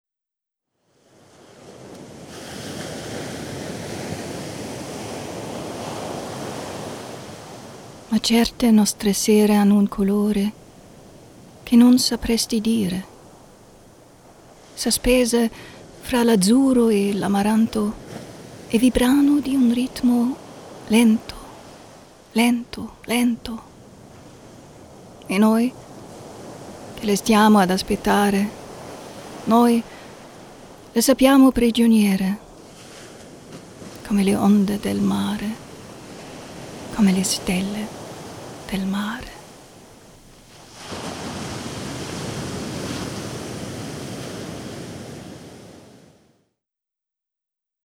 Erfahrene vielseitige Sprecherin. Stimmalter zwischen jung und mittel einsetzbar. Klare deutliche Stimme-
Sprechprobe: eLearning (Muttersprache):